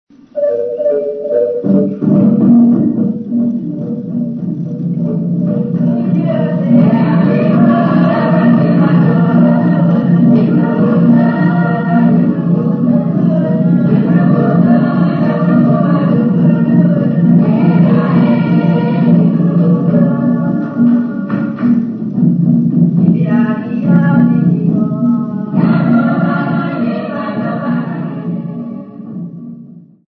Folk music
Sacred music
Field recordings
Africa Malawi Lilongwe mw
sound recording-musical
The Poor Clare Sisters perform at Midnight Mass. Church choral hymn with singing accompanied by a marimba xylophone